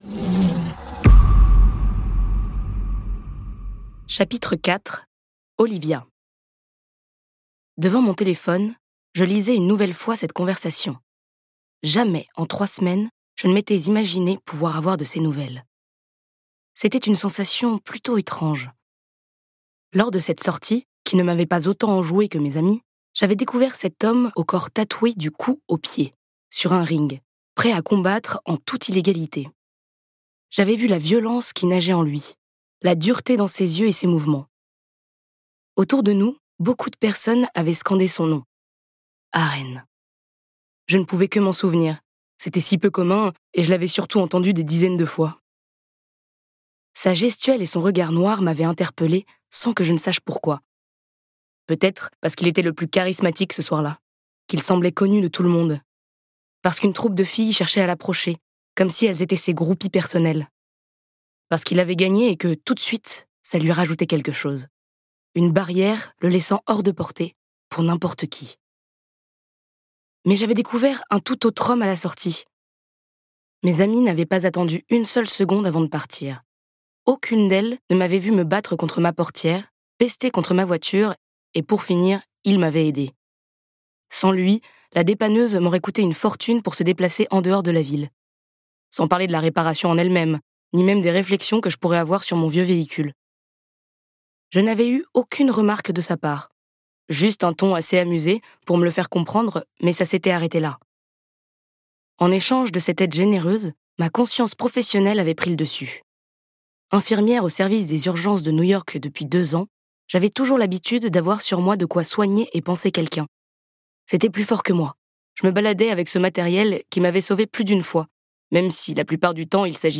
Elle lui donne envie d'un futur, il la pousse à être celle qu'elle est vraiment.Entre passion, trahisons et dangers, bienvenue dans le club des Relentless Riders !Ce livre audio est interprété par une voix humaine, dans le respect des engagements d'Hardigan.